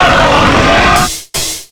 Cri d'Hippodocus dans Pokémon X et Y.